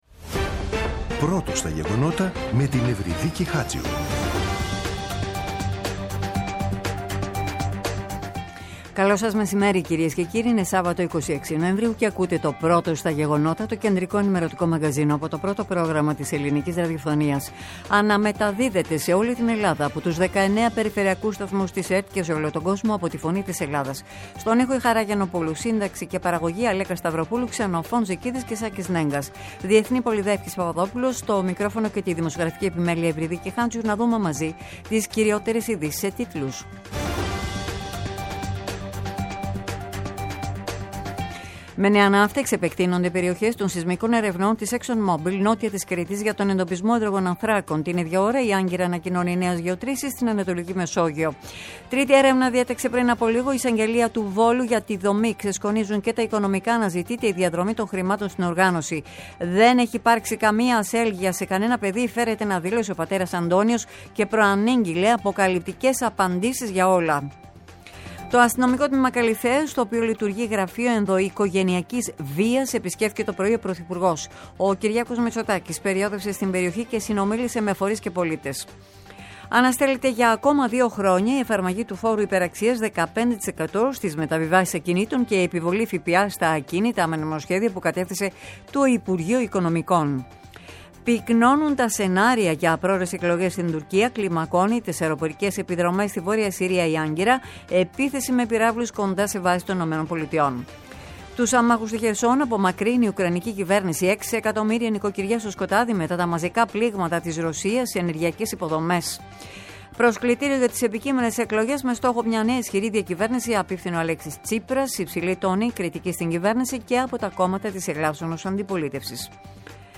“Πρώτο στα γεγονότα”. Το κεντρικό ενημερωτικό μαγκαζίνο του Α΄ Προγράμματος στις 14.00. Με το μεγαλύτερο δίκτυο ανταποκριτών σε όλη τη χώρα, αναλυτικά ρεπορτάζ και συνεντεύξεις επικαιρότητας.